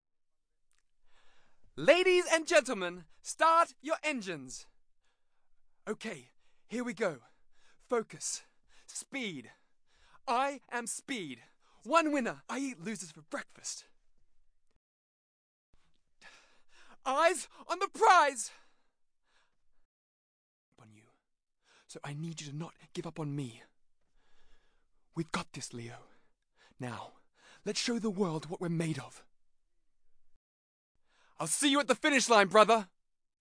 Voice Reel
RP - Video Game Reel